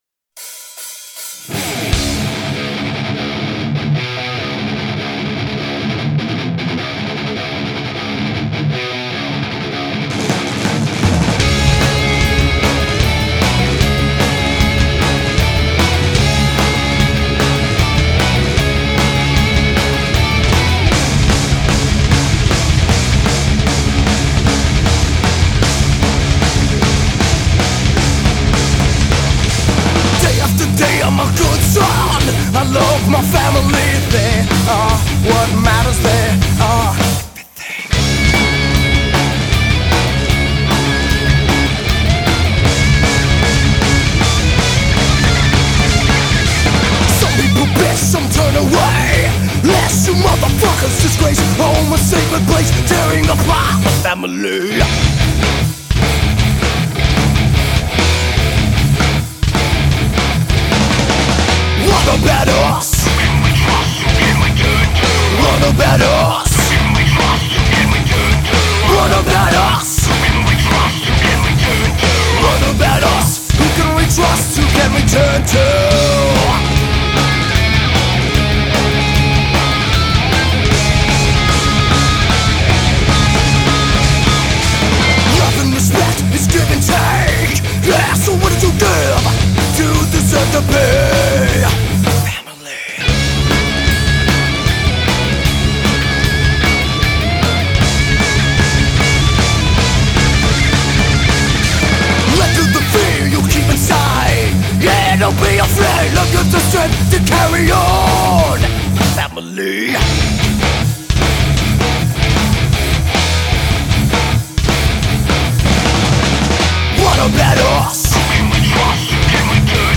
Download Heavy Metal cc